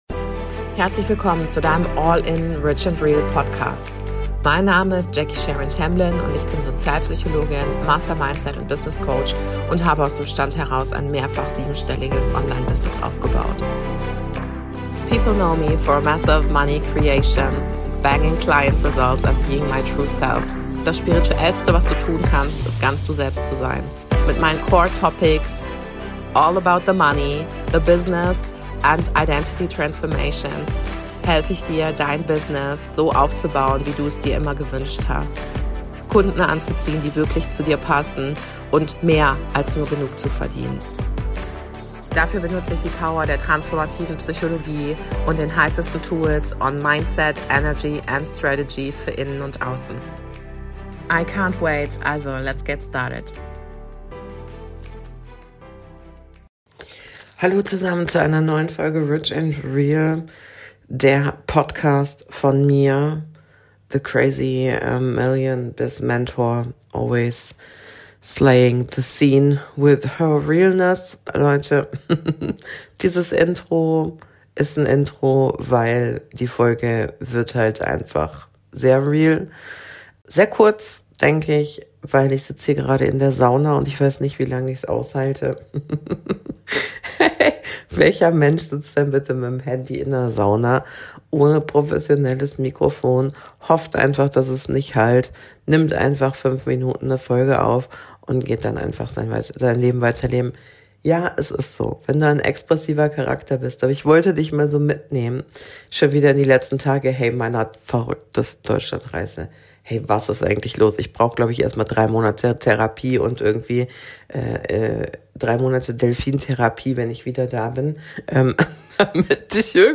direkt aus der Sauna